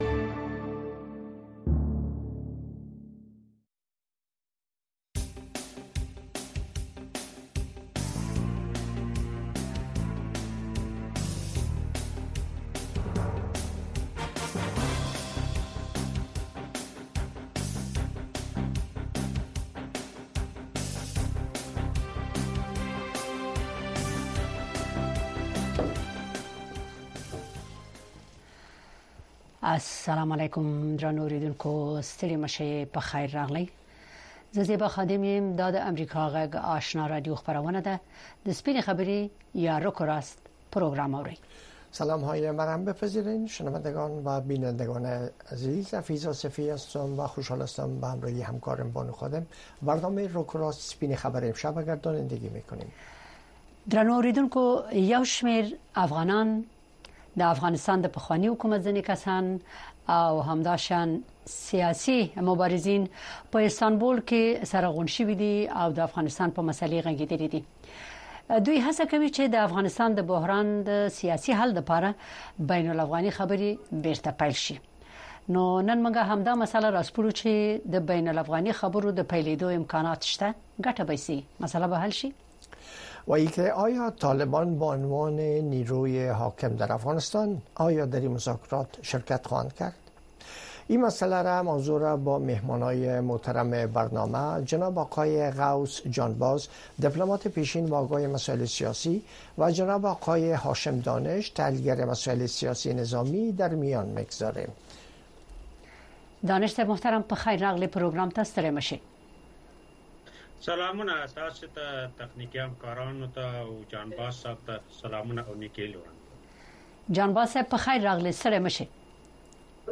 در برنامۀ رک و راست بحث‌های داغ صاحب‌نظران و تحلیلگران را در مورد رویدادهای داغ روز در افغانستان دنبال کرده می‌توانید. این برنامه زنده به گونۀ مشترک به زبان‌های دری و پشتو هر شب از ساعت هشت تا نه شب به وقت افغانستان پخش می‌شود.